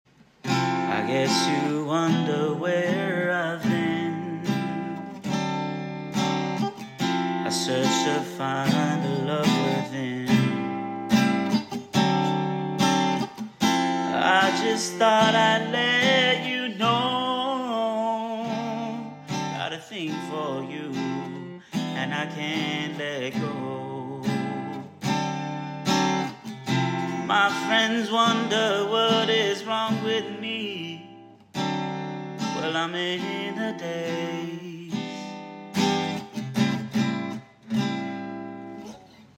Late Night Jam Sesh Sound Effects Free Download